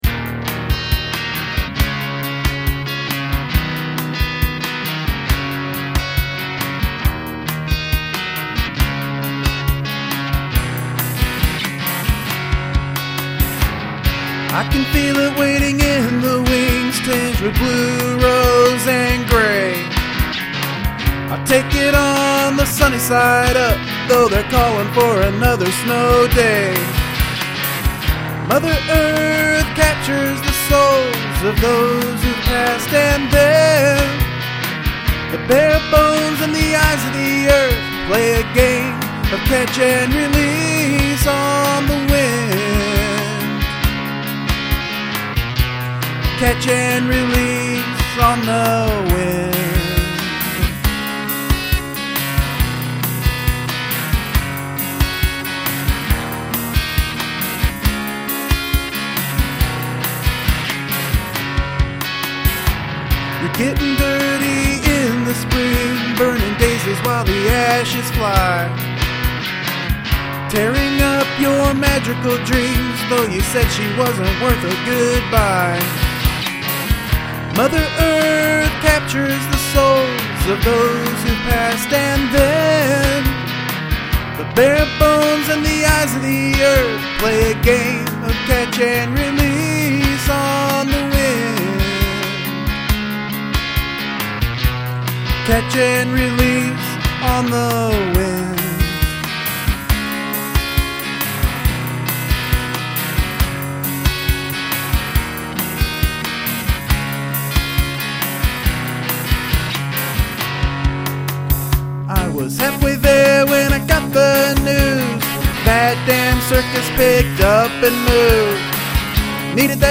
Yamaha acoustic using br900 presets, brdrums using the computer program to edit preset patterns, and of course my vox.
I like the guitar tone and the drum programming. The voices work very well together.
Love your voice.
Cool flowing guitar - like the vox and backing vox alot as well !!!
Natural sounding vox and guitar playing.